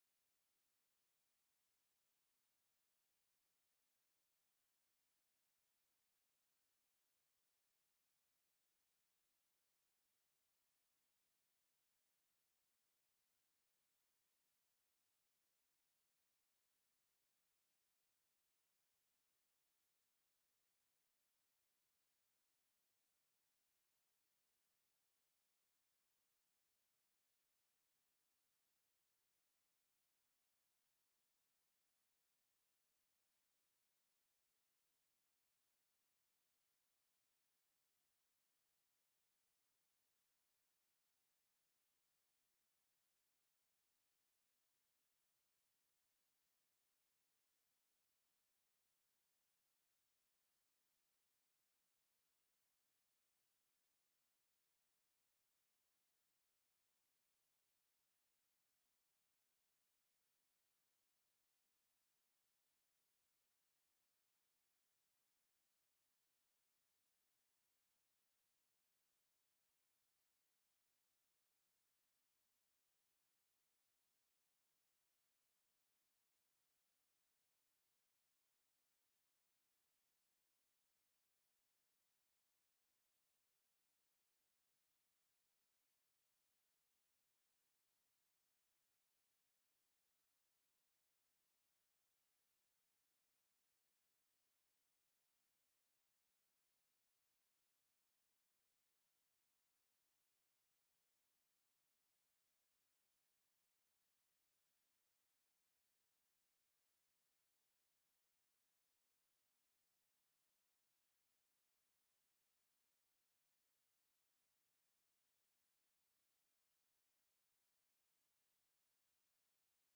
LittleWandaBigFuture_TrumpetOD.wav